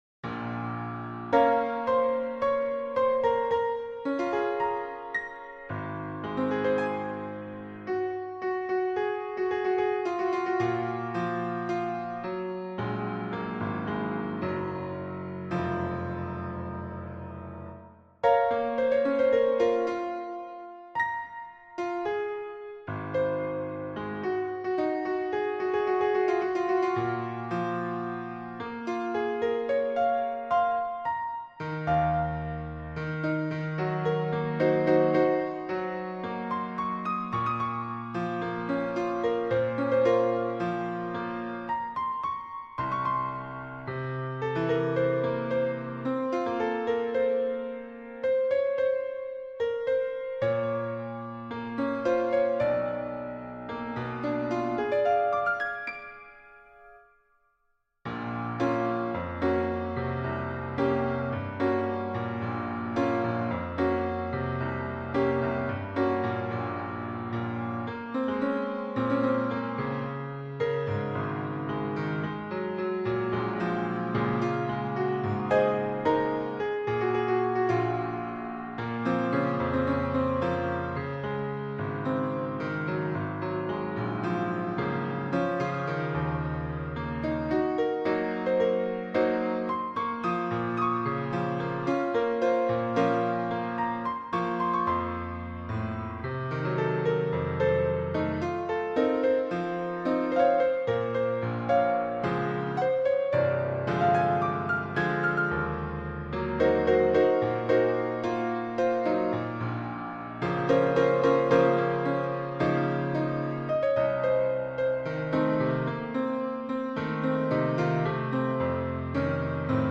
melodiyası zərif və axıcıdır